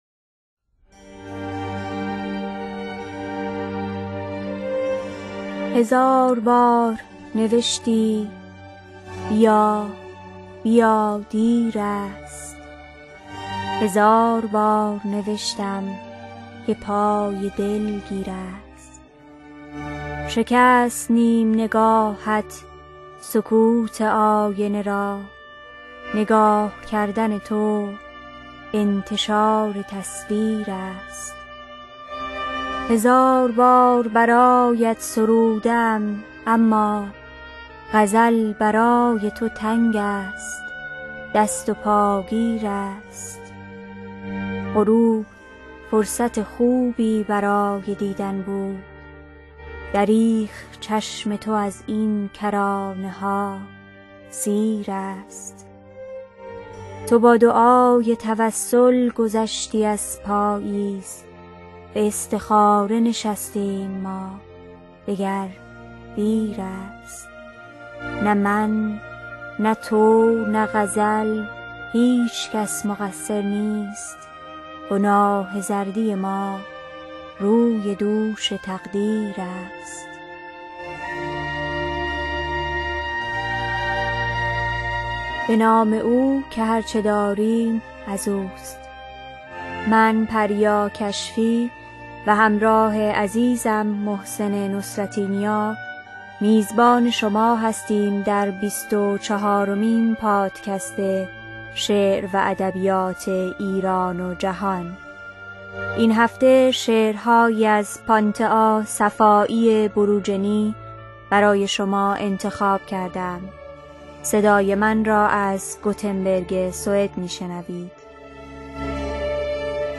در این پادکست که هر دو هفته یکبار مهمان آن خواهید بود اشعاری از شاعران ایران و جهان برای شما دکلمه می شود.